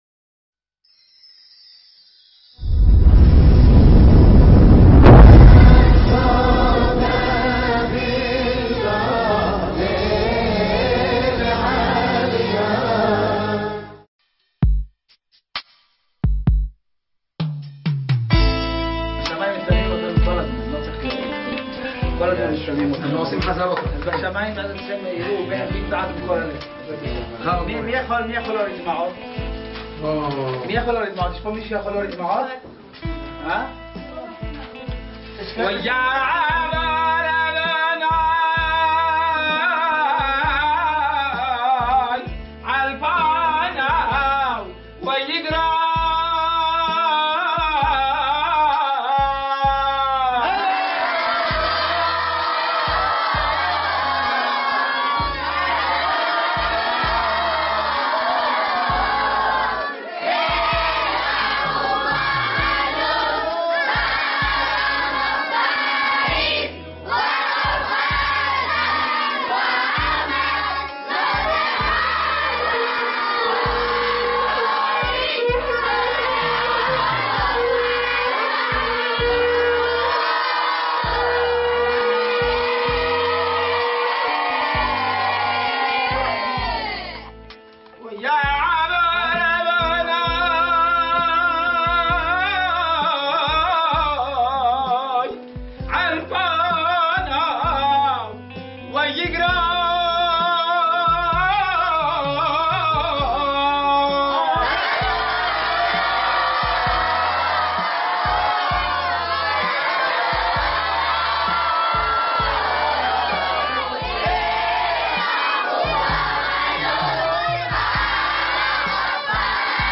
מעמד תפילה מיוחד שנערך על ציוני הצדיקים בהר הזיתים
אמירת פרקי תהילים ויג מידות עם תקיעת שופרות.________________________________________ ארגו...